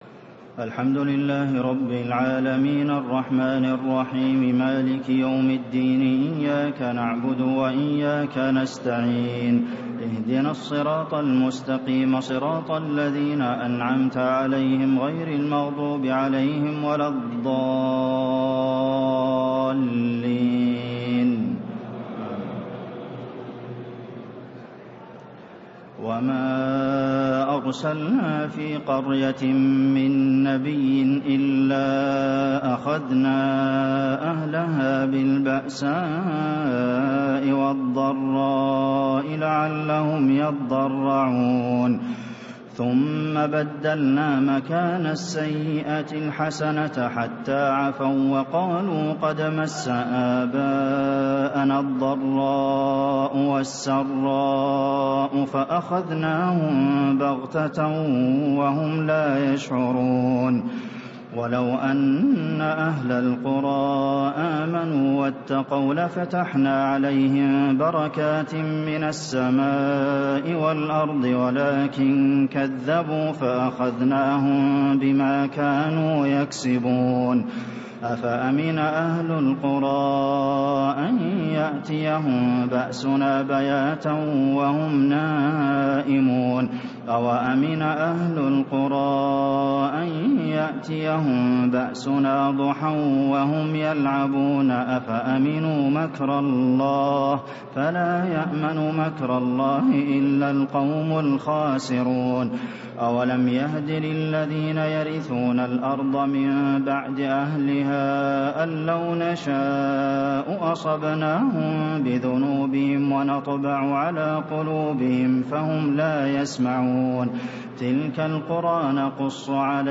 تهجد ليلة 29 رمضان 1436هـ من سورة الأعراف (94-188) Tahajjud 29 st night Ramadan 1436H from Surah Al-A’raf > تراويح الحرم النبوي عام 1436 🕌 > التراويح - تلاوات الحرمين